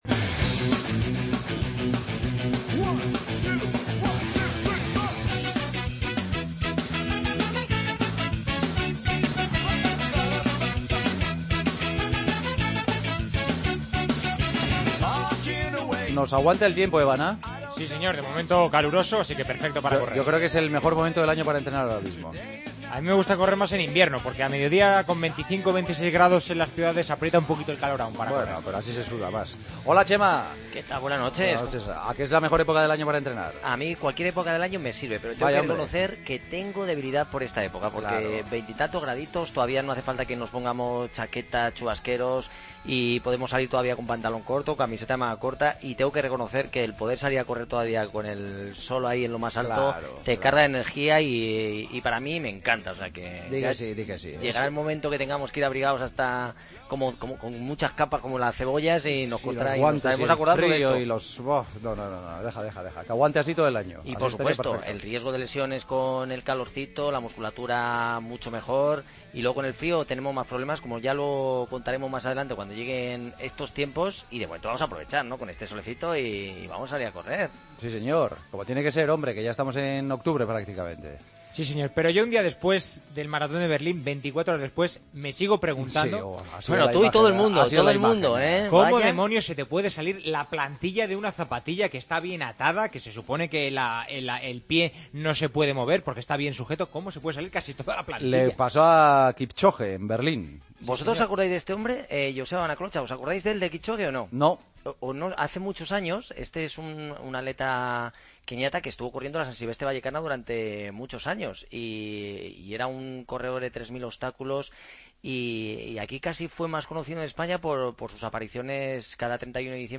Redacción digital Madrid - Publicado el 29 sep 2015, 02:29 - Actualizado 13 mar 2023, 20:31 1 min lectura Descargar Facebook Twitter Whatsapp Telegram Enviar por email Copiar enlace Con Chema Martínez y su consultorio con los oyentes. Además, resolvemos el misterio de las plantillas de Kypchoge en el reciente Maratón de Berlín.